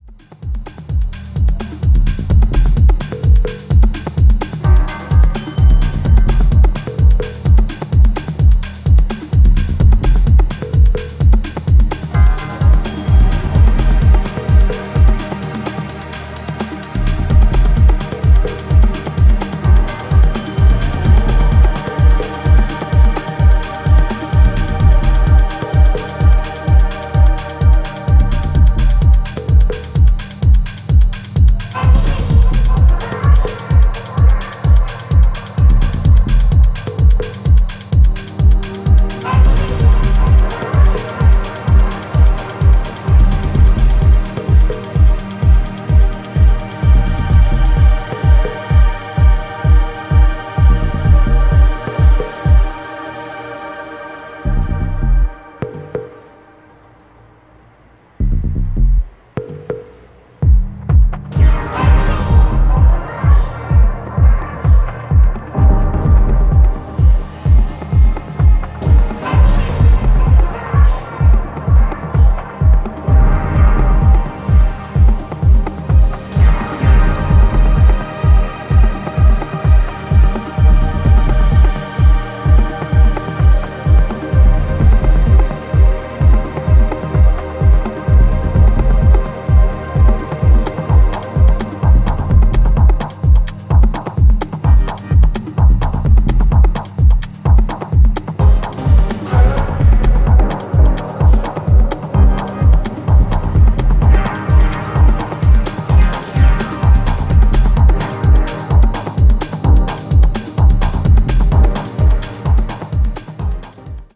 覚醒的なシンセパルスとハイトーンに加工されたヴォイスサンプルの響きが白昼夢のようでもあるディープ・ミニマル
各曲アプローチを絶妙に変えながらもアトモスフェリックかつ幻想的なトーンを保ち続けていますね。